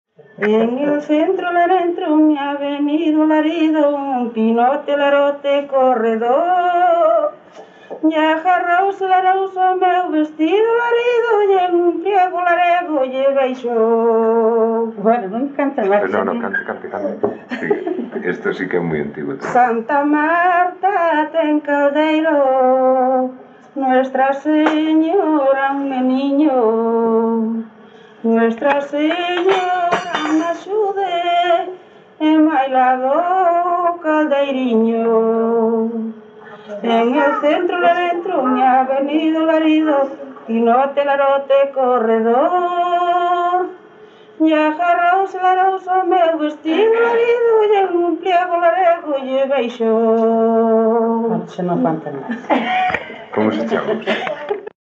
Áreas de coñecemento: LITERATURA E DITOS POPULARES > Coplas
Lugar de compilación: Forcarei - Soutelo de Montes - Magdalena, A
Soporte orixinal: Casete
Instrumentación: Voz
Instrumentos: Voz feminina
Compás: 3/4 3/4
Tempo: 4=103-114
Modo de interpretación: Tempo giusto